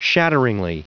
Prononciation du mot shatteringly en anglais (fichier audio)
Prononciation du mot : shatteringly
shatteringly.wav